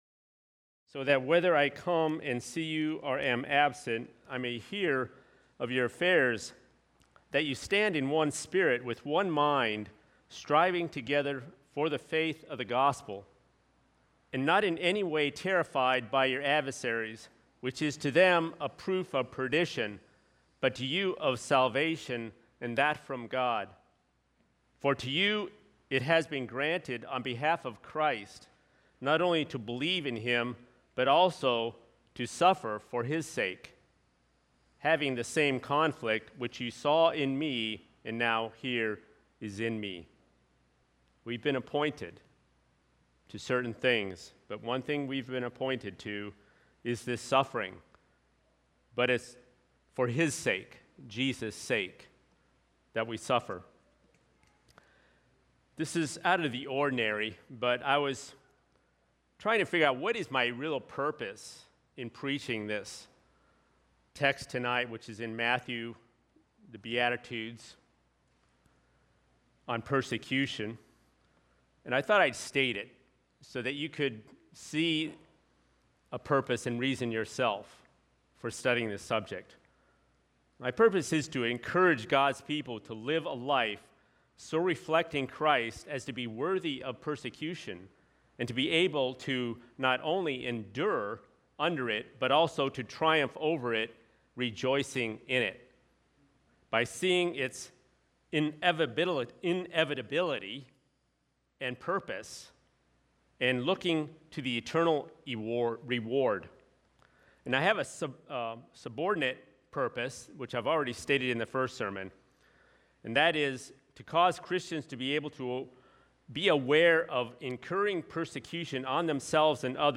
Sermon on the Mount: Blessed are the Persecuted – Part 2